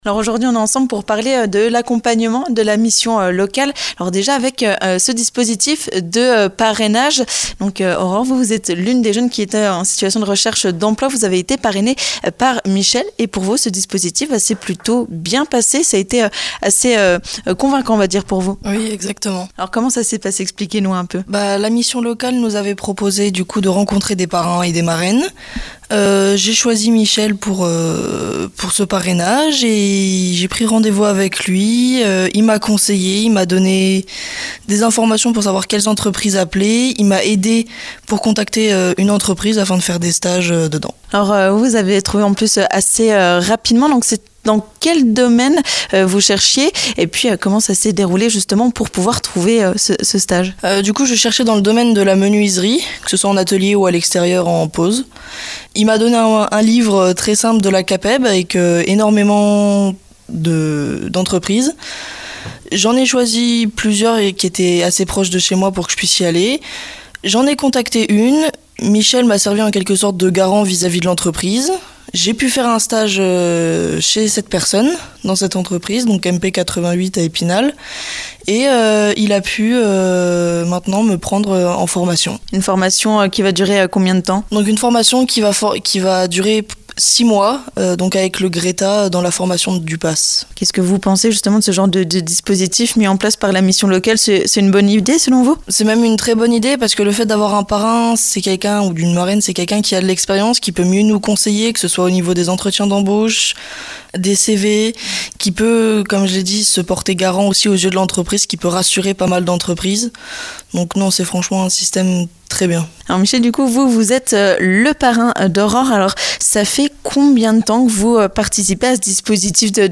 A cette occasion, nous avons tendu notre micro à